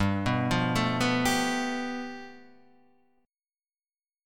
G 9th